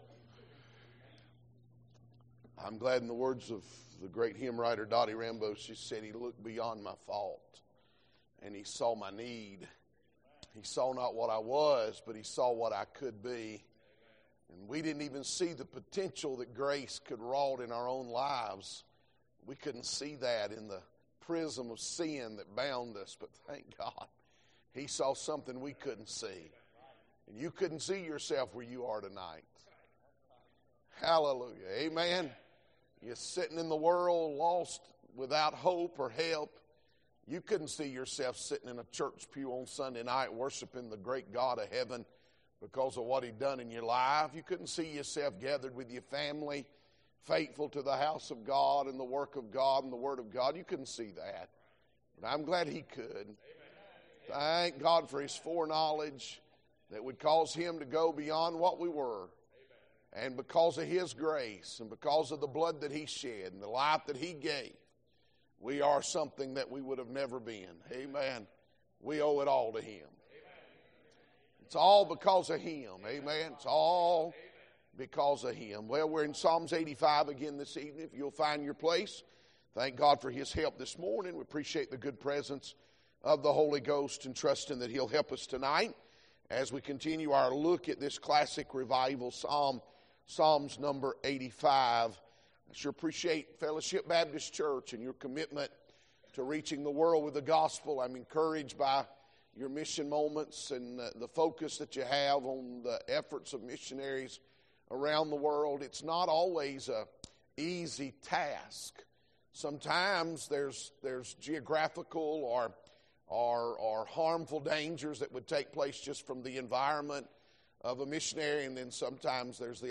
Sermons Archive • Fellowship Baptist Church - Madison, Virginia